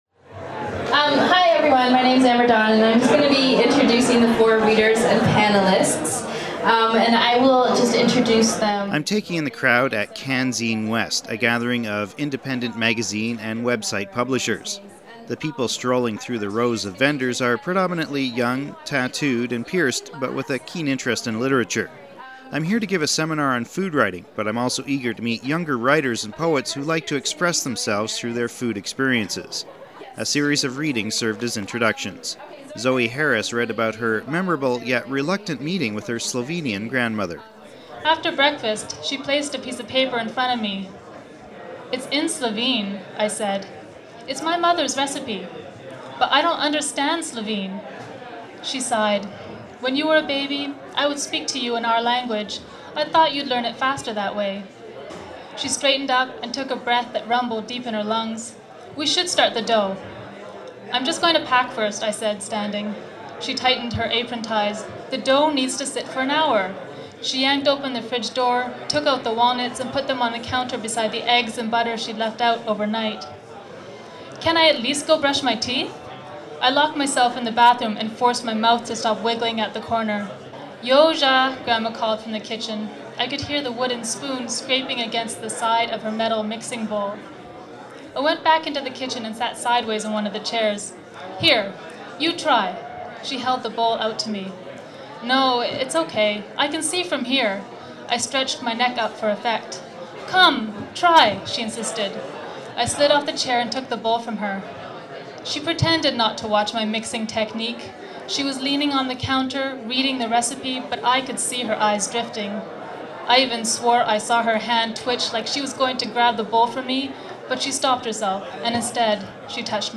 BpcoverThis week on Food For Thought, a visit to Canzine West, an independent ‘zine and website fair put on by Broken Pencil.  Meet some young food writers who read excerpts from their works.